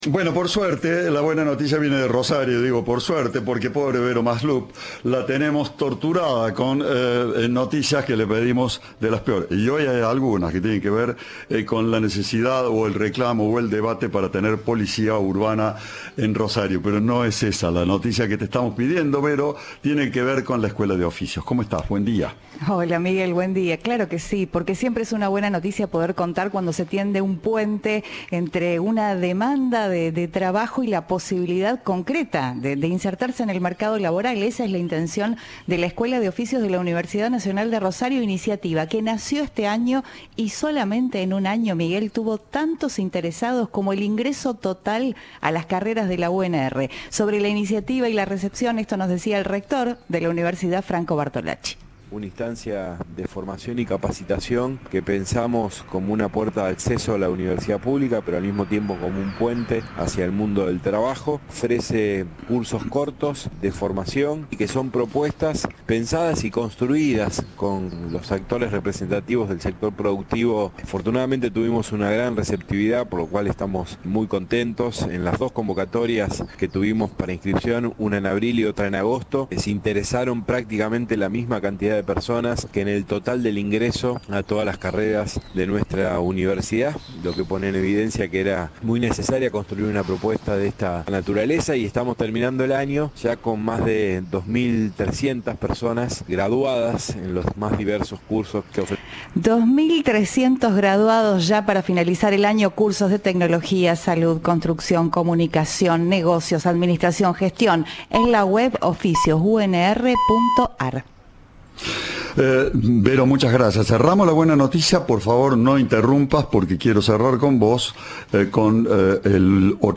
El rector de la UNR, Franco Bartolacci, dijo a Cadena 3 que "es una instancia tanto de acceso a la universidad pública como puente al trabajo".
Informe